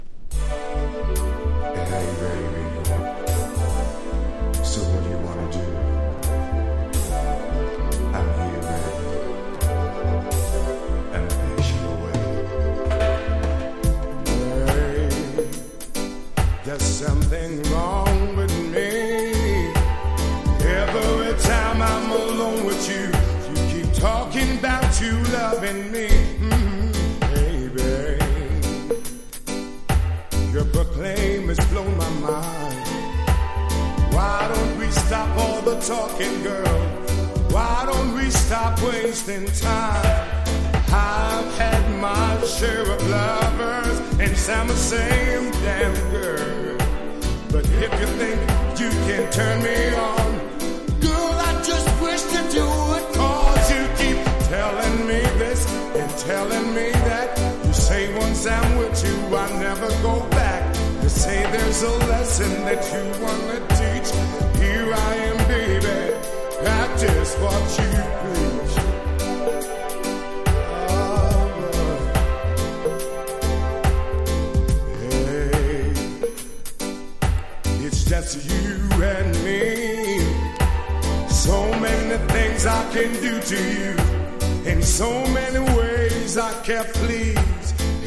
ジャマイカのレゲエアーティスト